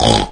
• Ronflement
snore.wav